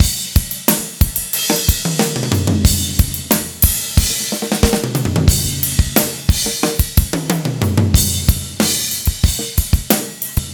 14 rhdrm91roll.wav